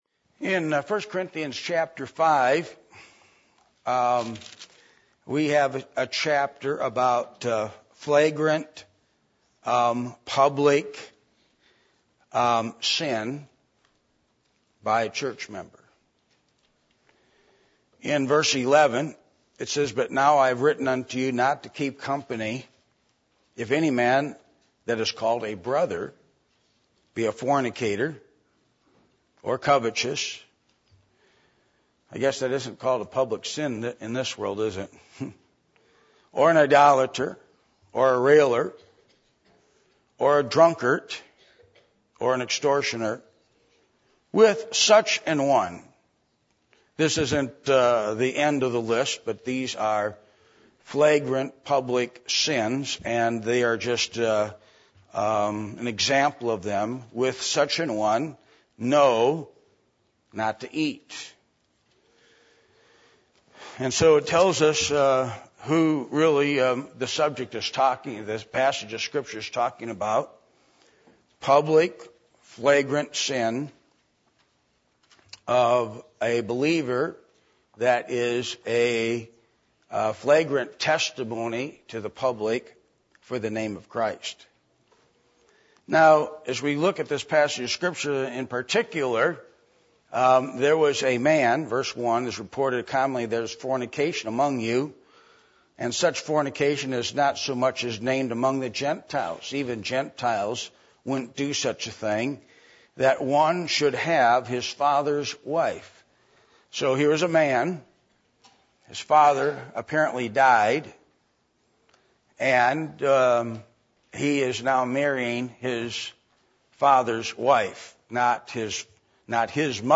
Passage: 1 Corinthians 5:1-13 Service Type: Midweek Meeting